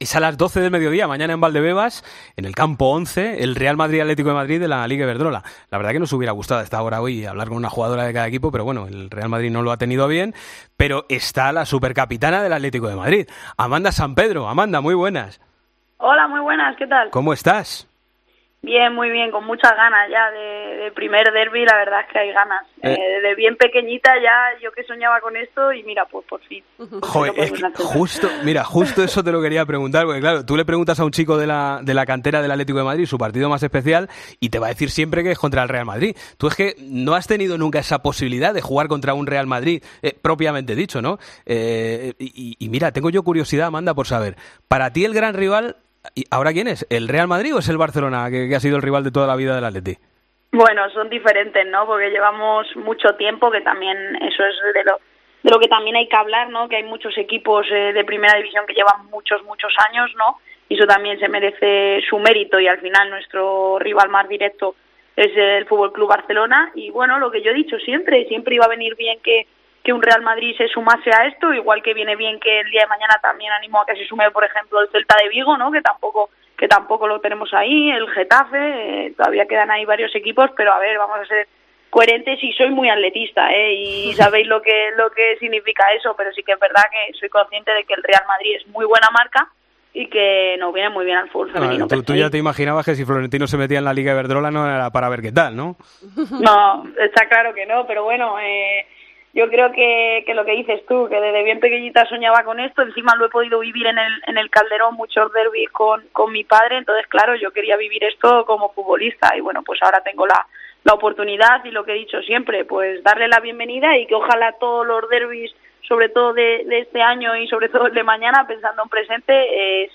La capitana del Atlético de Madrid habló en Deportes COPE de las sensaciones previas al derbi frente al Real Madrid: "Queremos dedicarle la victoria a todos los atléticos".